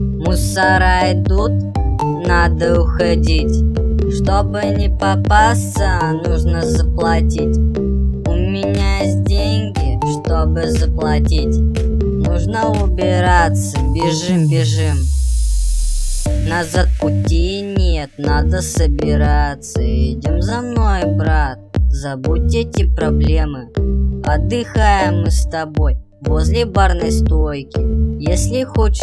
• Качество: 320, Stereo
русский рэп
забавный голос